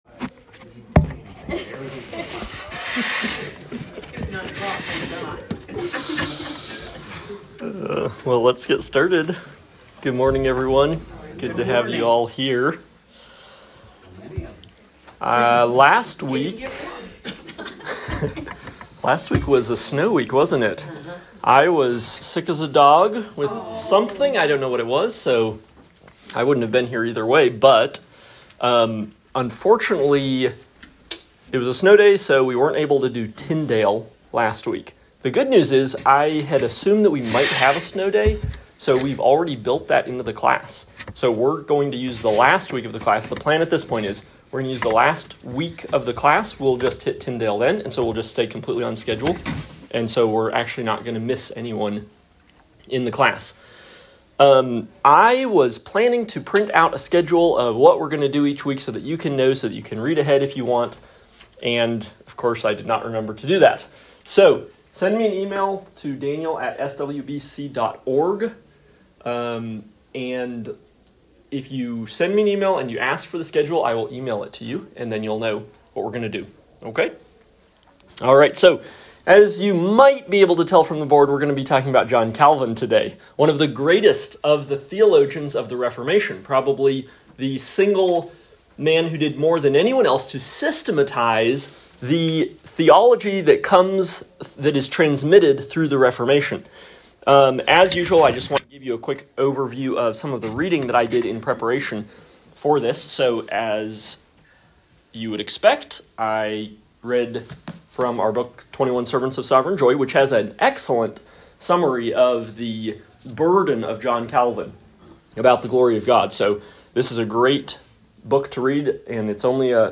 Lecture 3 – John Calvin
Lecture-3-John-Calvin.m4a